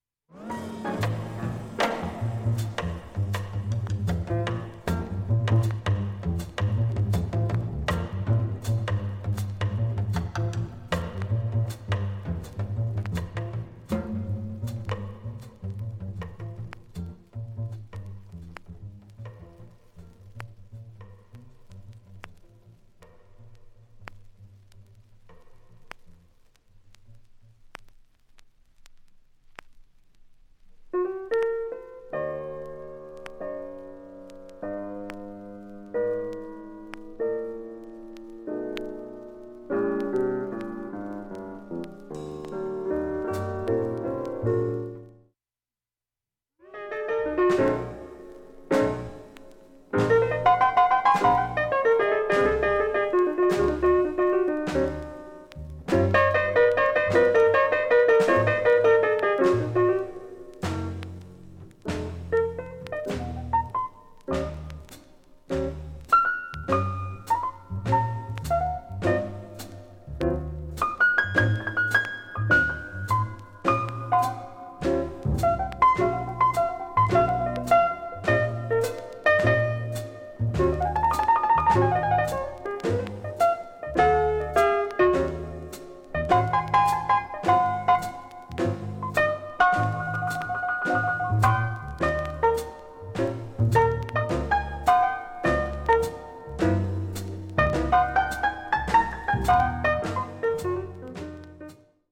バックチリ音もかなり少なく
いい音質です全曲試聴済み
かすかなプツが２２回出ます。
◆ＵＳＡ盤初期プレス Mono